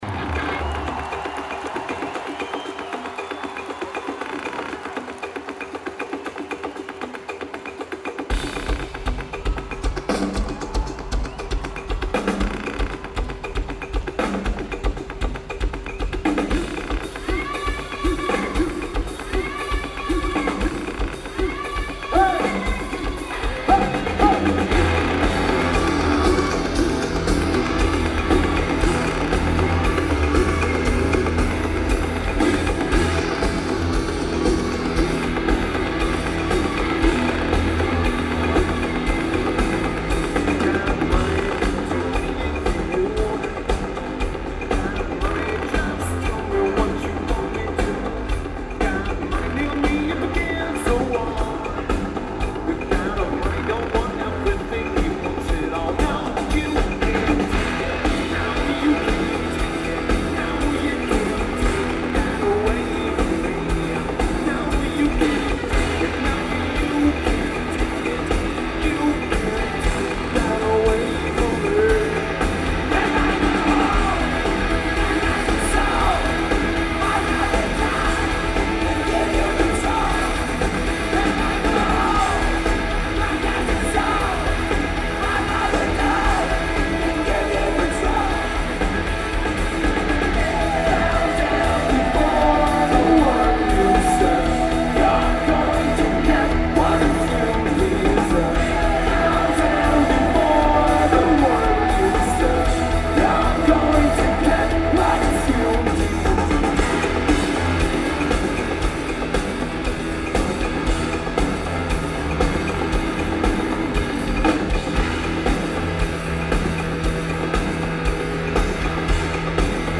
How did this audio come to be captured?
Phones 4u Arena Manchester, England United Kingdom Lineage: Audio - AUD (SP-CMC-8 + SP-SPSB-11 + Edirol R09HR)